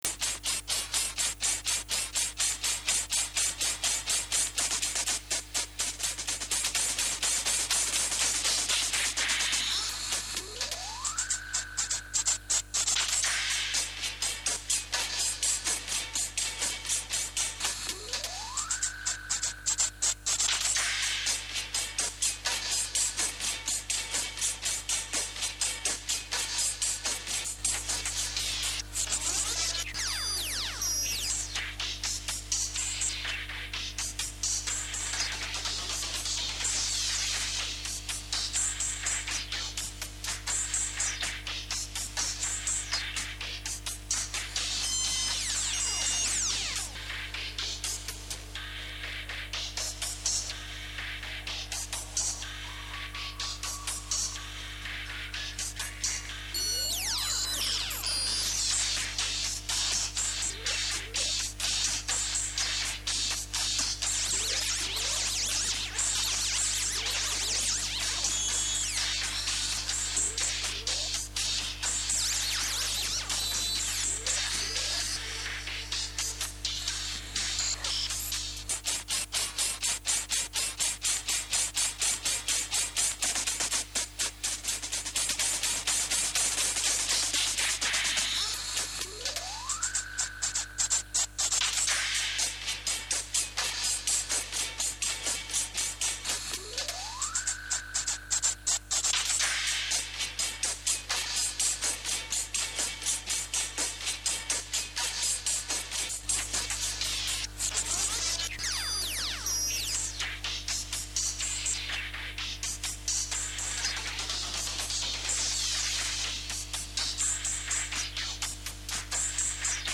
Альбом обрёл своё звучание на домашней студии